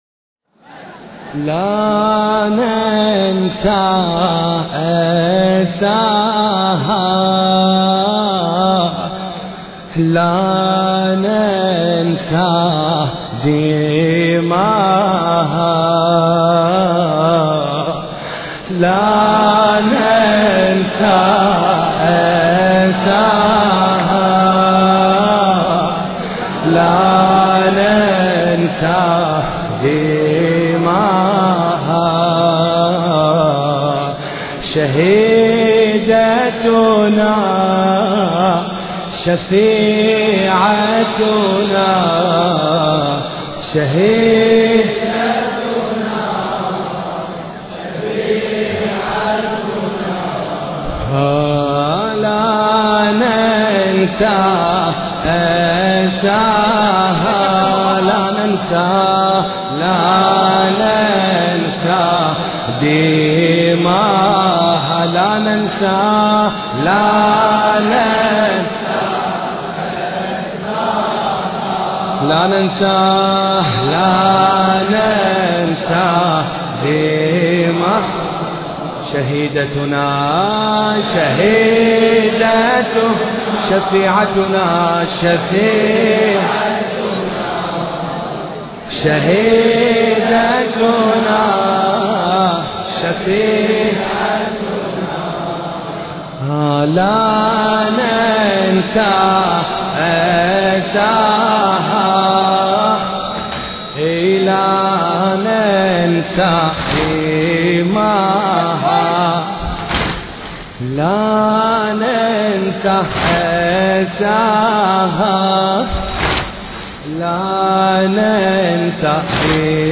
مراثي فاطمة الزهراء (س)